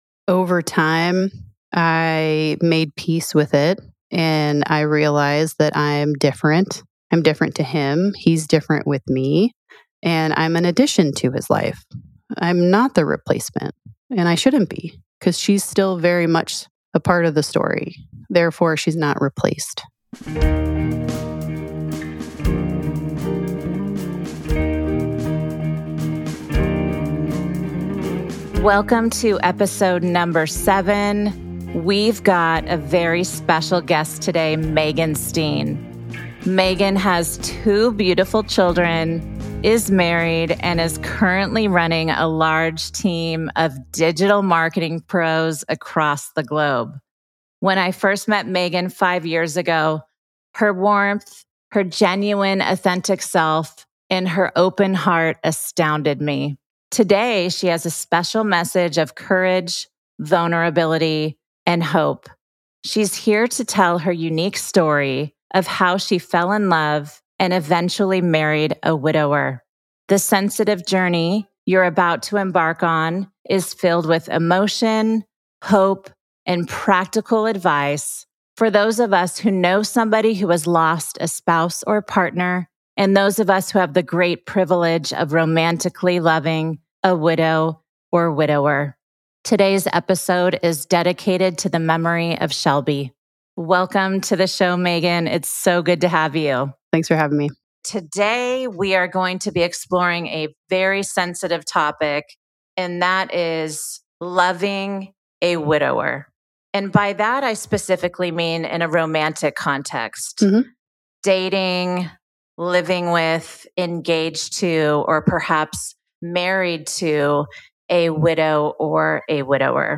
The episode wraps up with interesting statistics on widowers and widowers, dispelling the myth that they are "undateable." Finally, excerpts from a poignant poem on grief add a reflective touch.